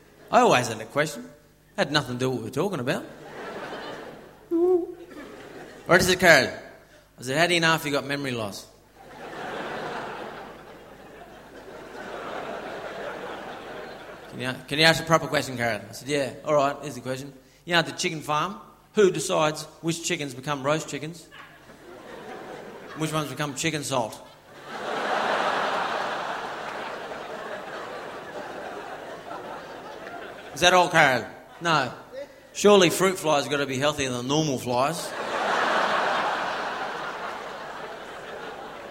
Category: Comedians   Right: Personal
Tags: Carl Barron Carl Barron comedy Australia comedian Fifi Paul Kelly funny observational humour